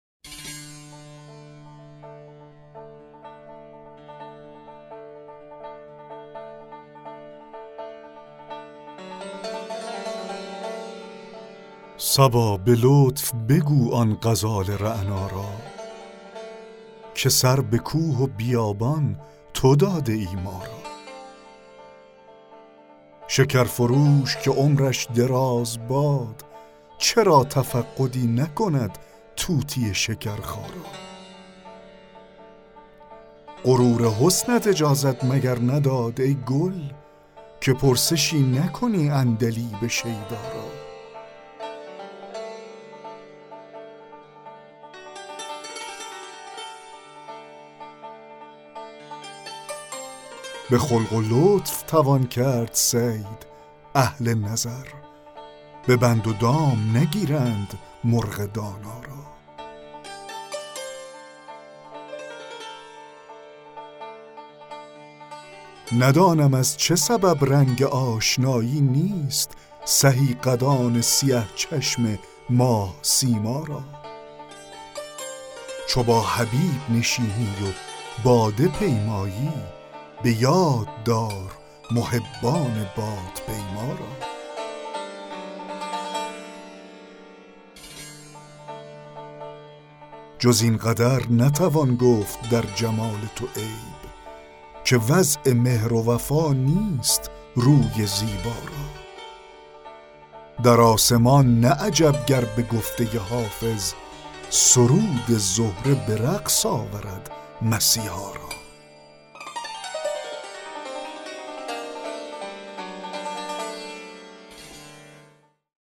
دکلمه غزل 4 حافظ
دکلمه غزل صبا به لطف بگو آن غزال رعنا را